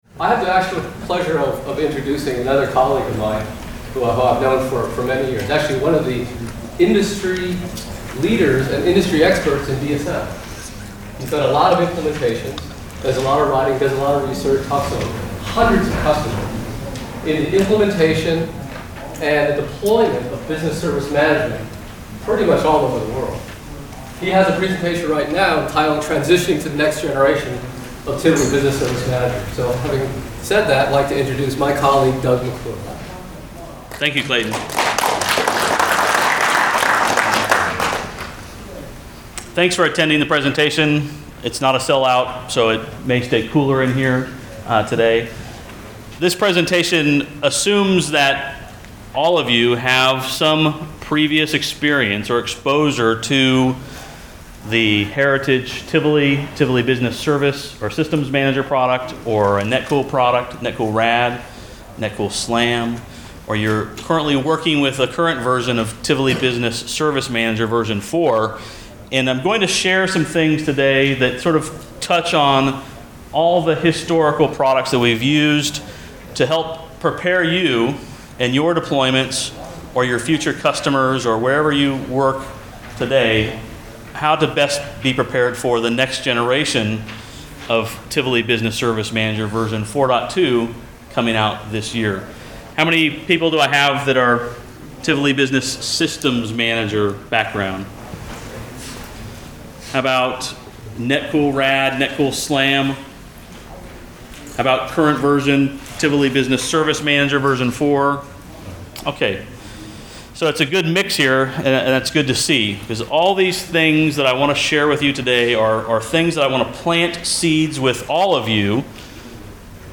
I’m making my IBM Tivoli Pulse 2008 session on TBSM available for those who were unable to attend the user conference this year or missed my session.